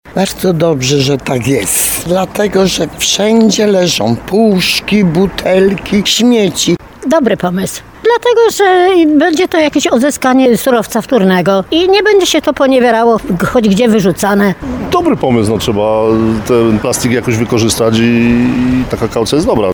Tarnowianie, z którymi rozmawialiśmy, popierają wprowadzenie kaucji.